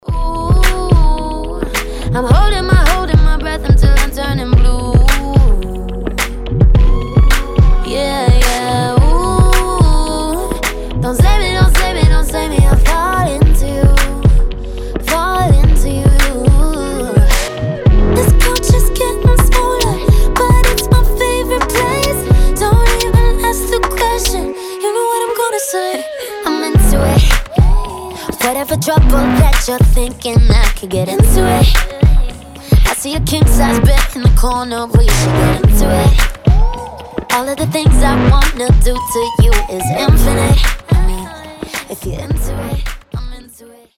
• Качество: 320, Stereo
поп
женский вокал
чувственные
красивый женский голос
Latin Pop